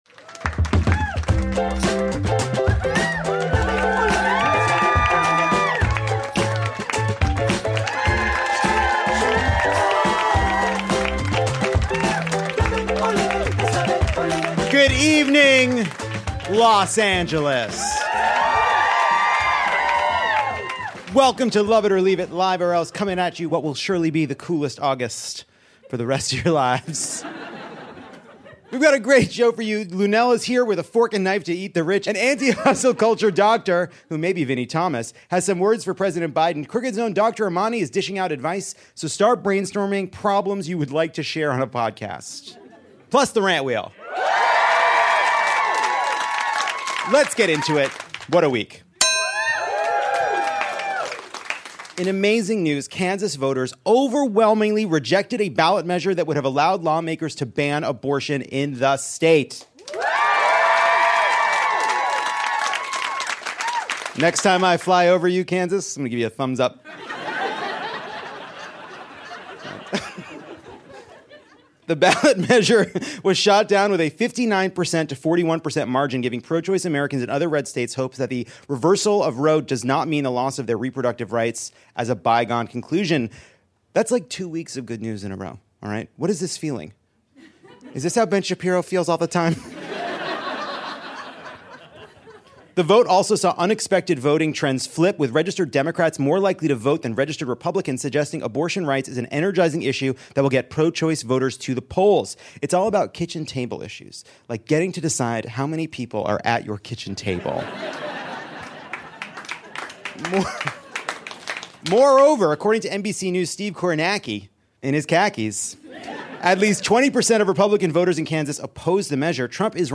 Nancy Pelosi might be trotting the globe, but Lovett or Leave It is home sweet home at Dynasty Typewriter. There’s no such thing flying as flying too close to the sun when Luenell buys a ticket to the celebrity private jet scandal.